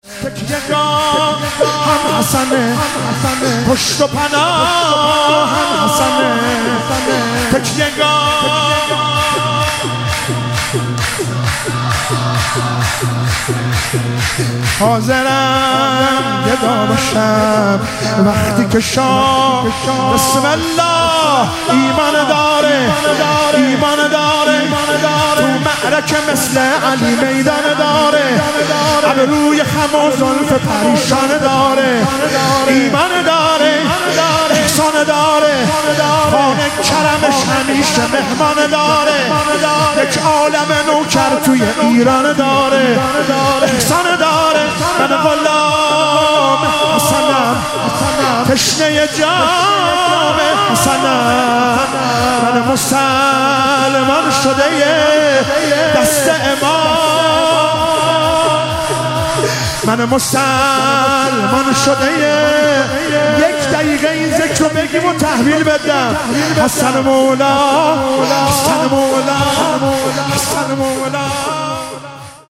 مراسم مناجات خوانی شب شانزدهم و جشن ولادت امام حسن مجتبی علیه السلام ماه رمضان 1444
شور- تکیه گاهم حسنه، پشت و پناهم حسنه